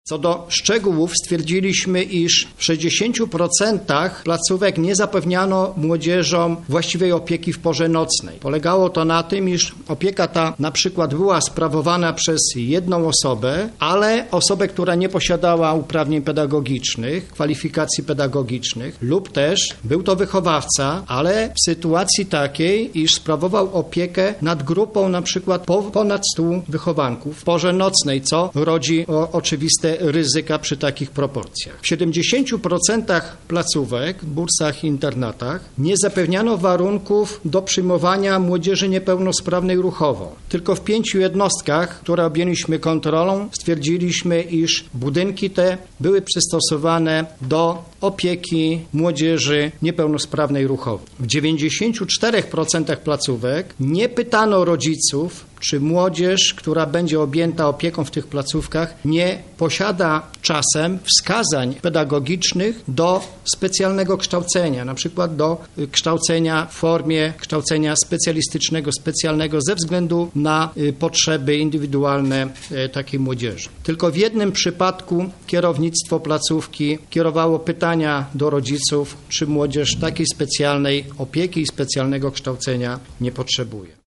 O szczegółach mówi dyrektor lubelskiej delegatury NIK Edward Lis.